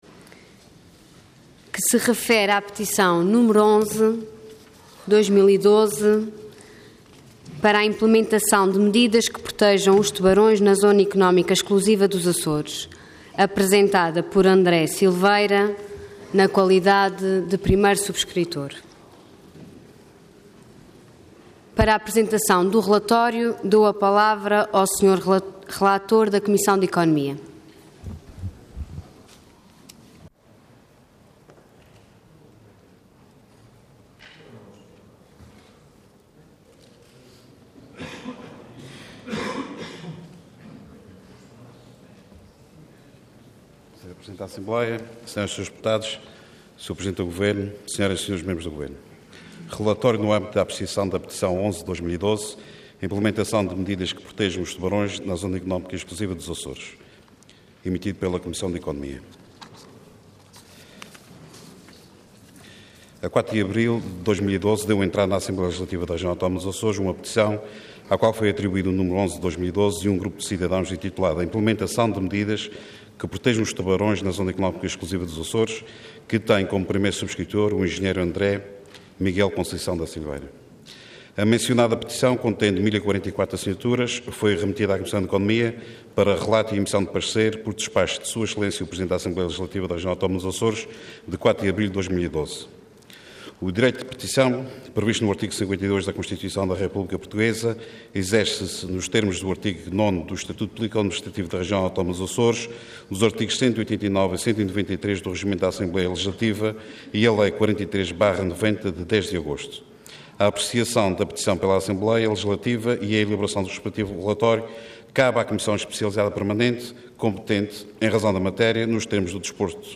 Intervenção Petição Orador José Ávila Cargo Relator Entidade Comissão de Economia